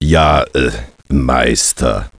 1 channel
golemMove5.mp3